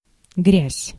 Ääntäminen
Vaihtoehtoiset kirjoitusmuodot (vanhentunut) durt Synonyymit filth soil earth shit Ääntäminen GenAm: IPA : /dɝt/ US : IPA : [dɝt] RP : IPA : /dɜːt/ NYC: IPA : /dɜɪ̯t/ Tuntematon aksentti: IPA : /dɜ(ɹ)t/